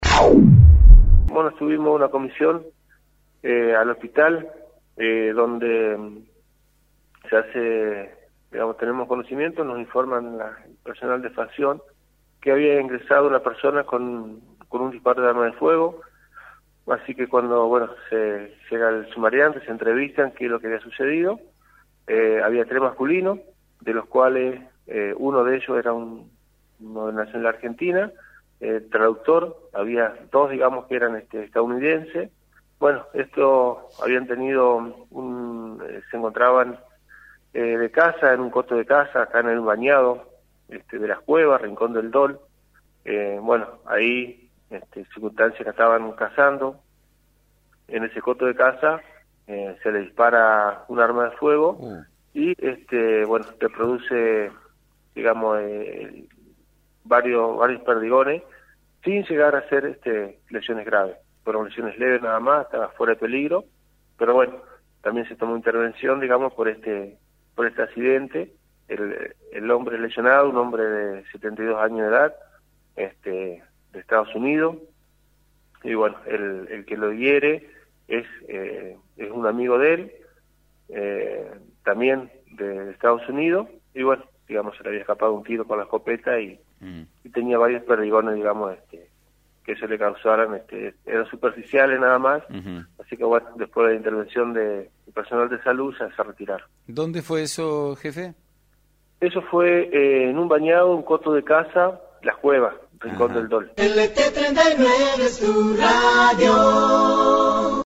Claudio Passadore – Jefe Departamenal de Policía.